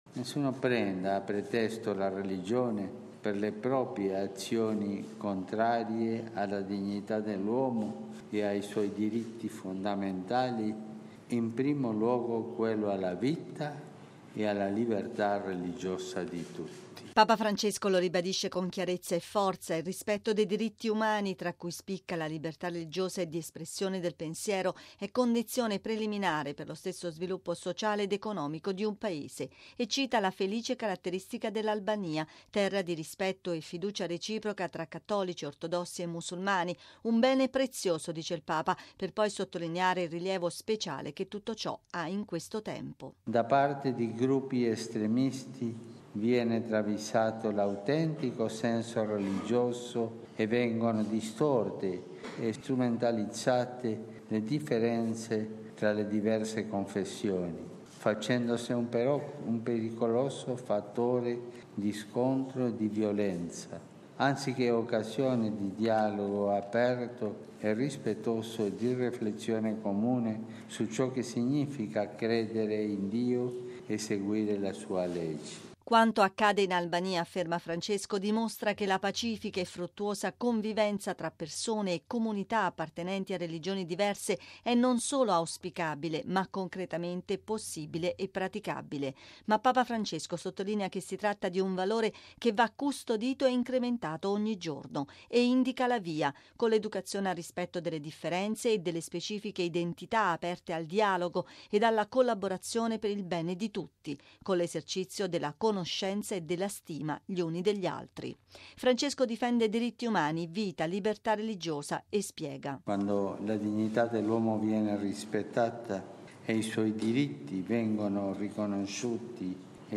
Diritti umani, libertà religiosa, convivenza tra religioni: tra i temi forti toccati nel primo dei sei discorsi di Papa Francesco in Albania. Ascoltiamo il Papa